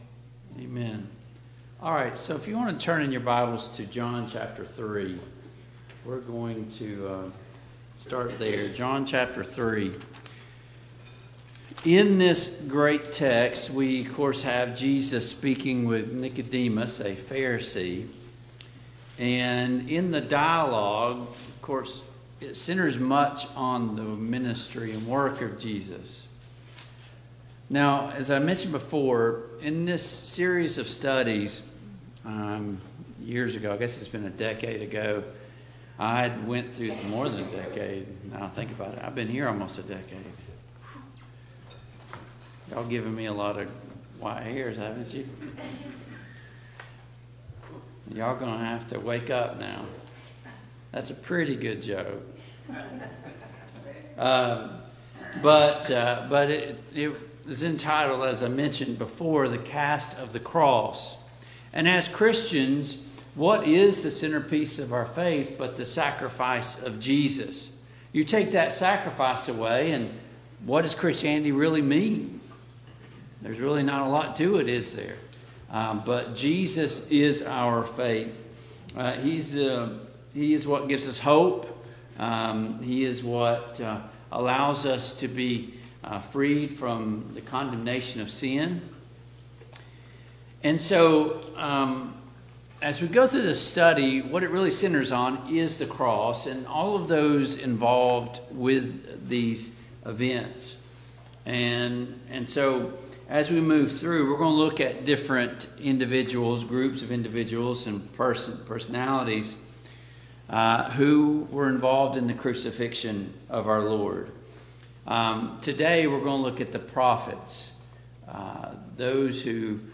John 3:14-17 Service Type: Sunday Morning Bible Class Topics: Jesus Christ , The Crucifixion , The Prophets « 32.